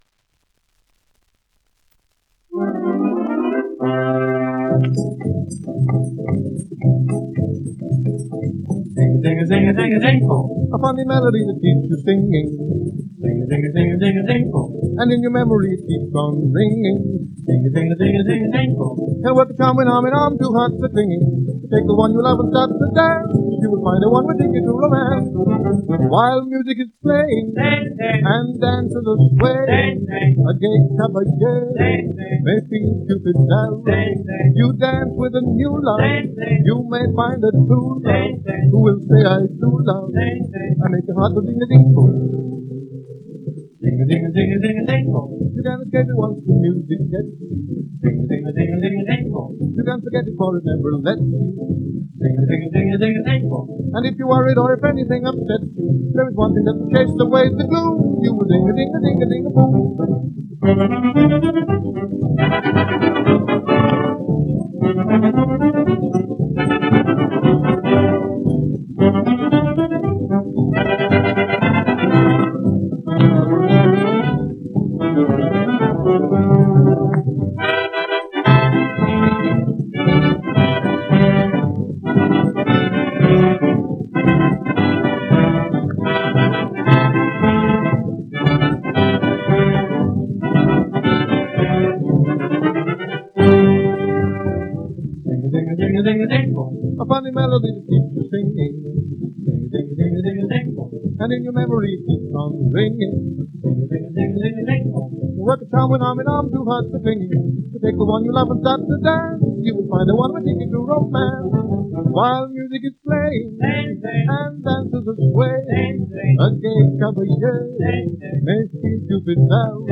1 disco : 78 rpm ; 25 cm